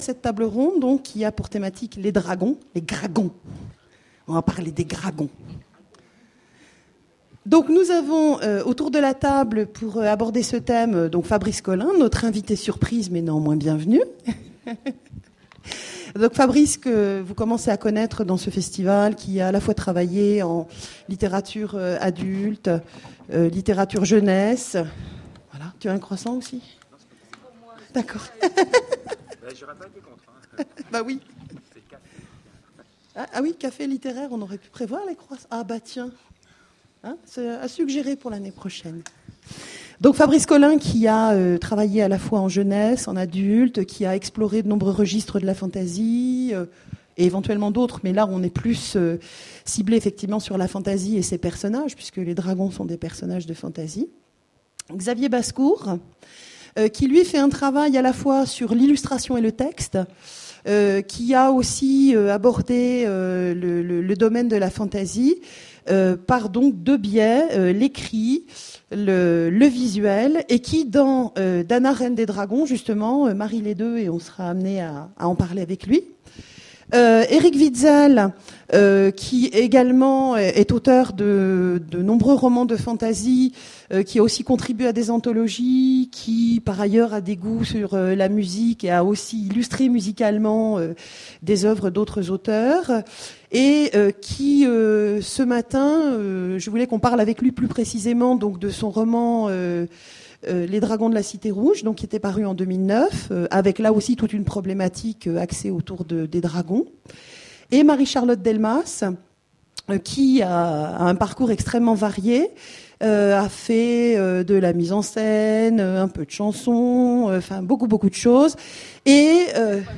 Conférence Imaginales 2010 : Les dragons… Entre peur et fascination…
Conférence Imaginales 2010 : Les dragons…
Voici l'enregistrement de la conférence Les dragons… Entre peur et fascination… aux Imaginales 2010